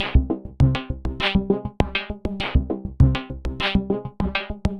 tx_synth_100_augment_CGAb2.wav